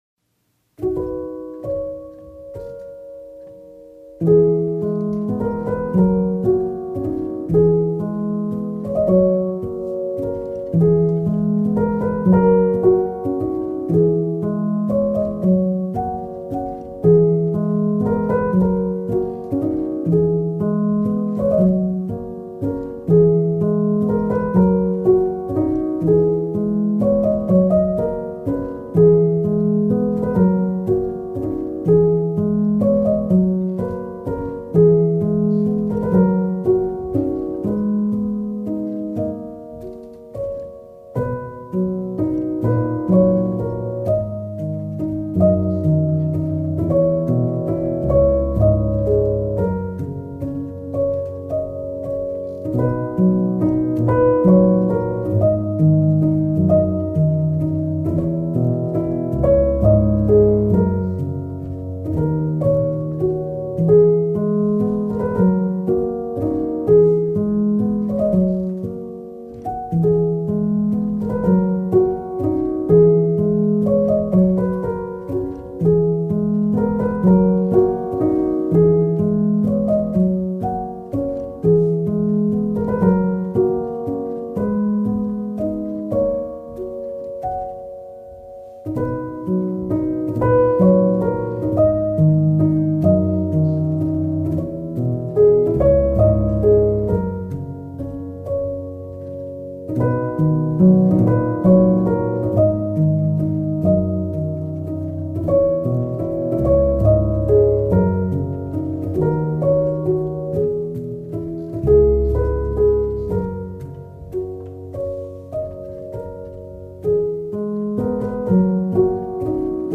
موسیقی کنار تو
موسیقی بی کلام آرامش بخش ابری و بارانی پیانو